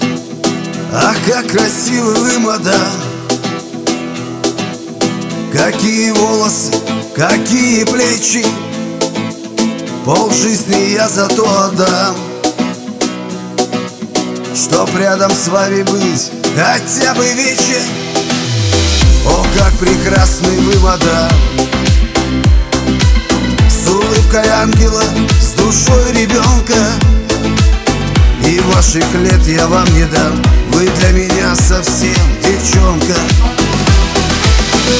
Жанр: Шансон / Русские
# Russian Chanson